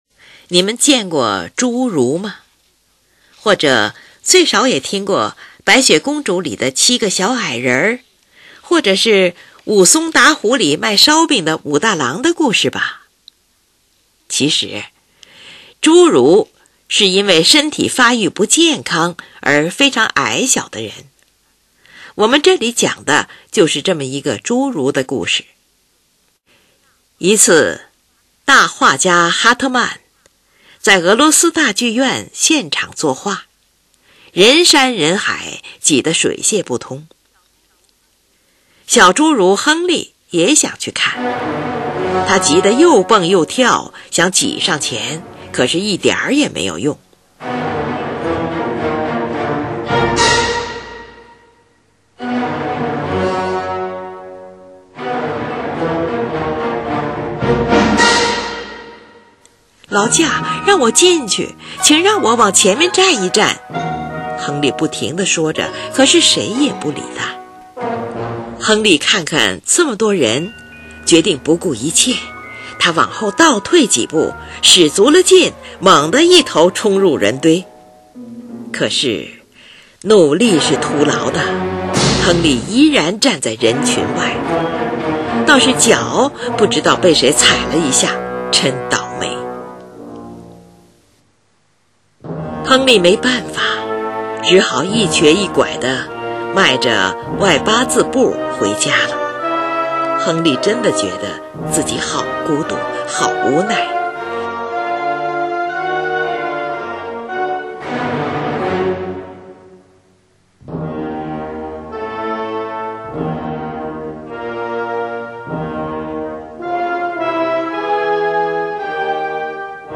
乐曲开始时像突然发作时的急剧跳跃和长时间的停顿交替，很容易使人想起蹦跳和着急的样子。